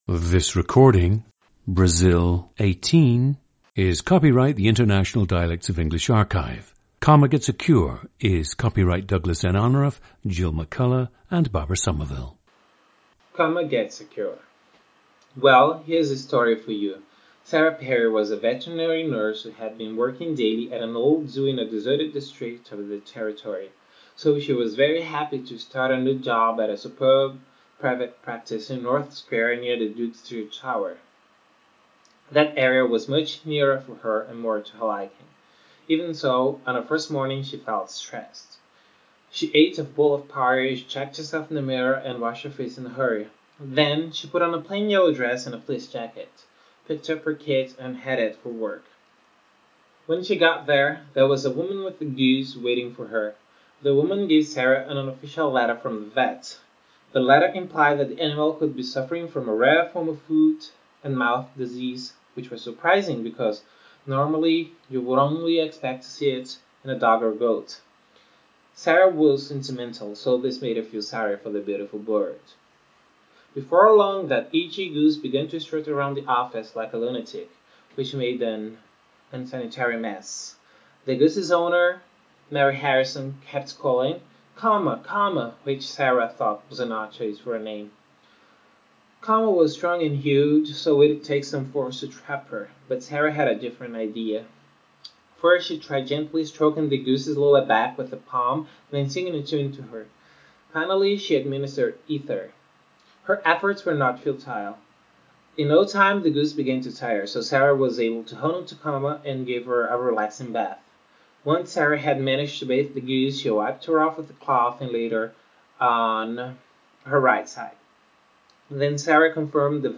GENDER: male
He watches television and listens to radio broadcasts from a lot of English-speaking countries. His primary language is (Brazilian) Portuguese.
• Recordings of accent/dialect speakers from the region you select.